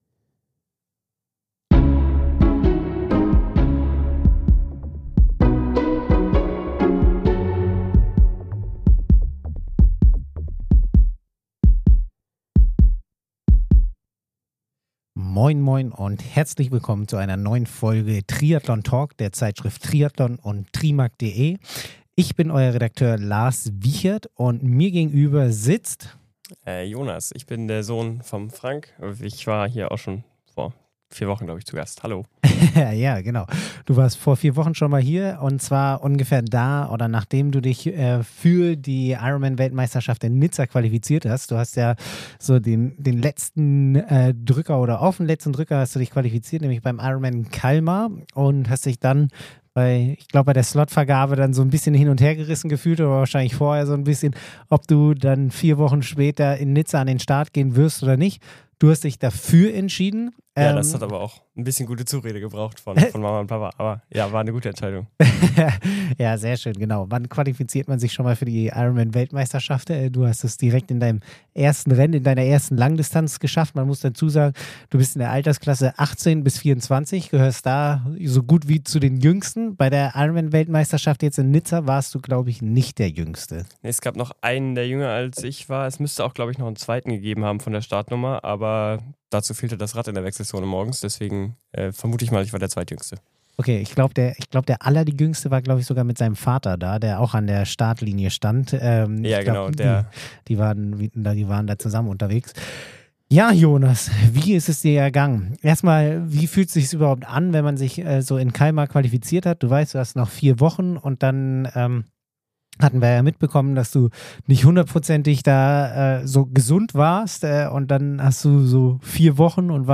Zwei Experten aus der Redaktion sprechen über das aktuelle Triathlongeschehen.
Eine Persönlichkeit aus dem Triathlonsport im ausführlichen Gespräch.